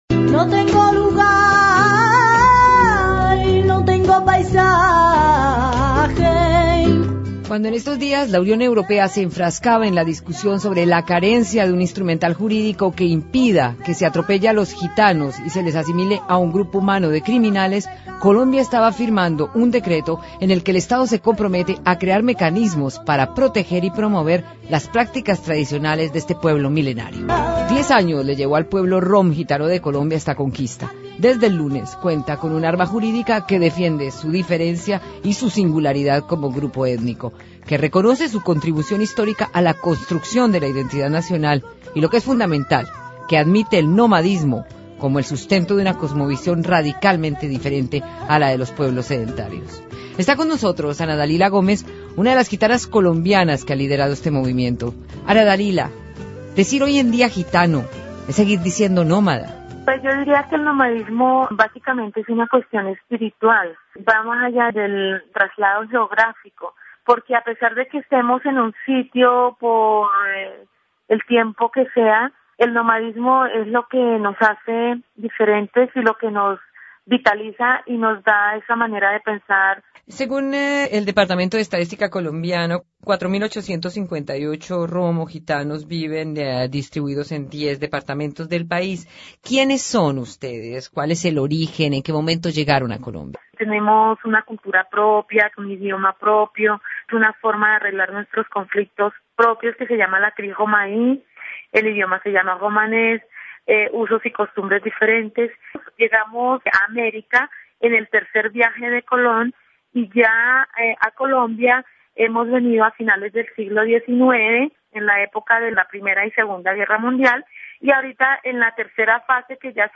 Escuche el informe de Radio Francia Internacional sobre este asunto.